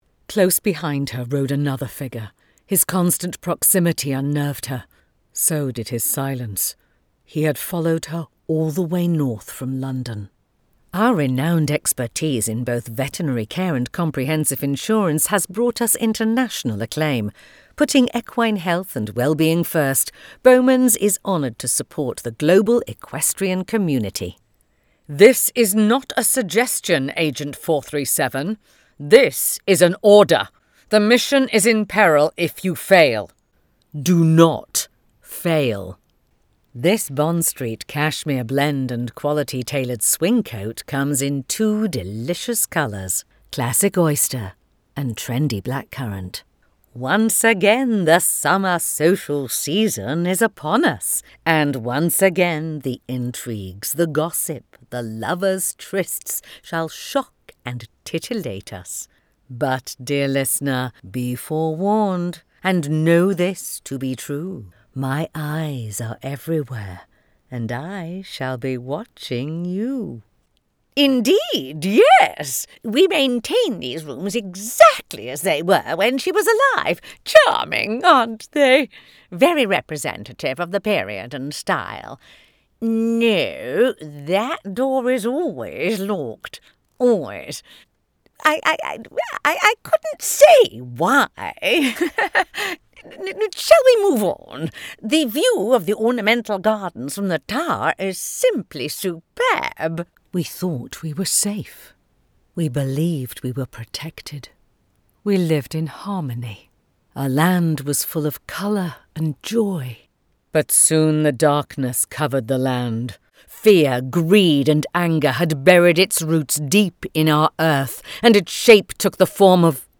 British Accent Showreel
Female
Neutral British
British RP
Friendly
Warm
very-english-british-voice-over-demo-2024.mp3